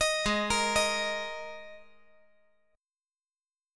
G#sus2 Chord
Listen to G#sus2 strummed